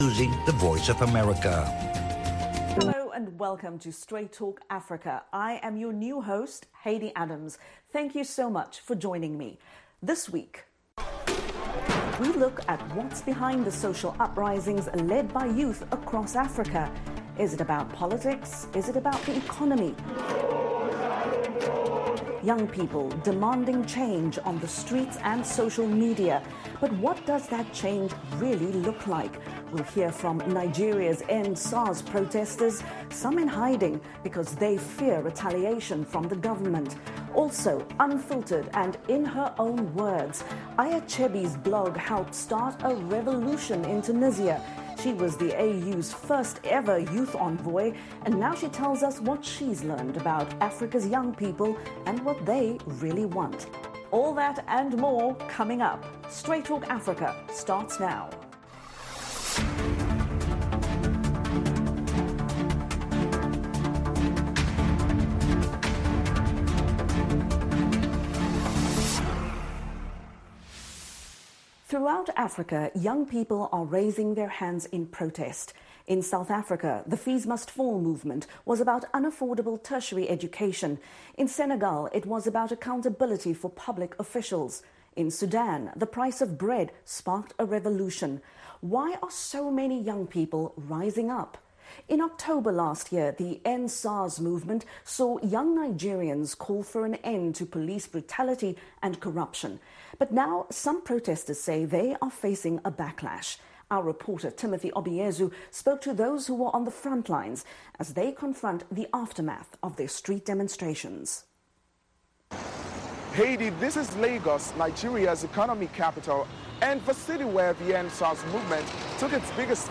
Straight Talk Africa [simulcast] Wed.,
Join veteran journalist Shaka Ssali on Straight Talk Africa every Wednesday as he and his guests discuss topics of special interest to Africans, including politics, economic development, press freedom, health, social issues and conflict resolution.